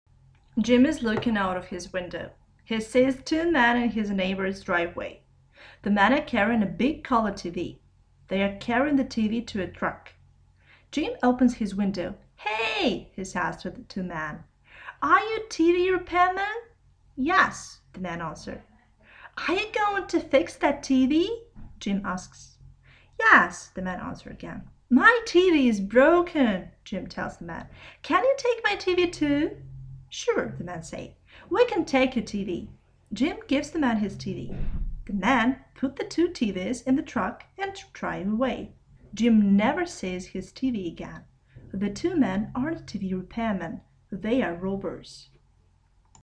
Вы услышите разговор в магазине подарков.